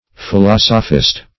Search Result for " philosophist" : The Collaborative International Dictionary of English v.0.48: Philosophist \Phi*los"o*phist\ (f[i^]*l[o^]s"[-o]*f[i^]st), n. [Cf. F. philosophiste.]
philosophist.mp3